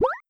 Water7.wav